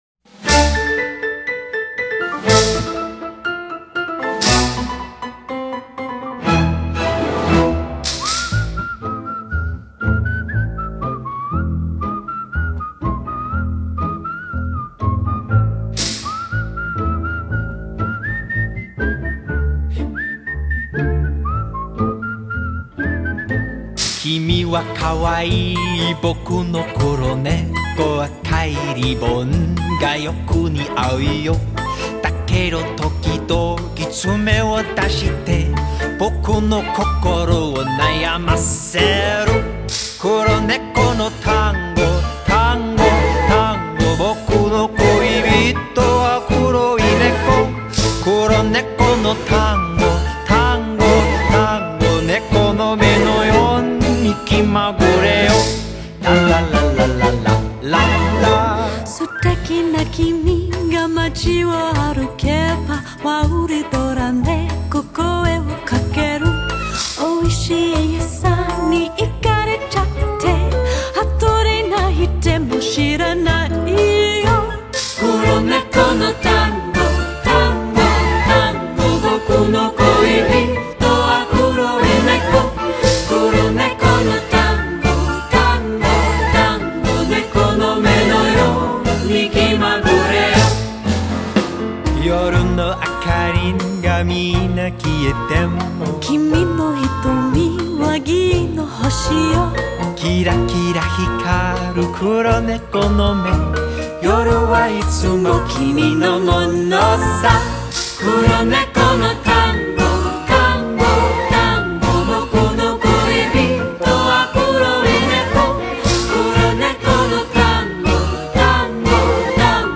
Pop, Easy Listening, World, Jazz